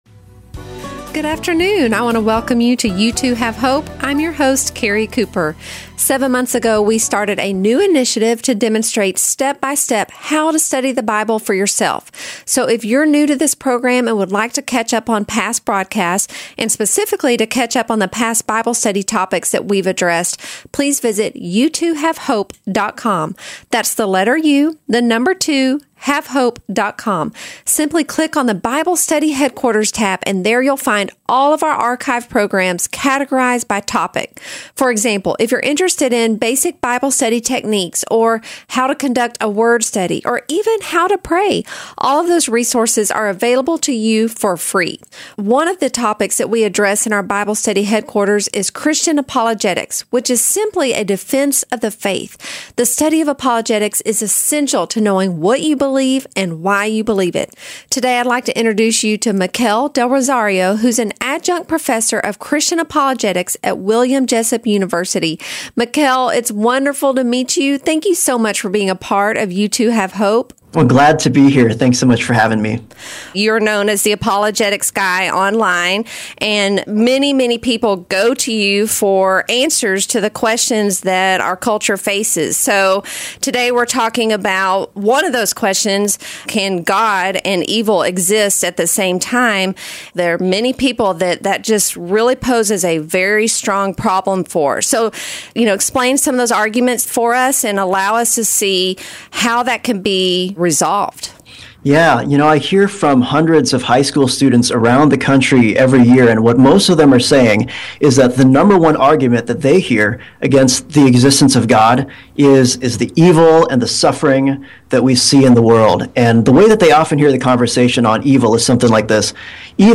U2HaveHope airs on 91.3 FM KDKR (Dallas/Ft.Worth) and their network stations across the country. This show focuses on teaching listeners the "how to" of bible study as well as introducing basic apologetic topics.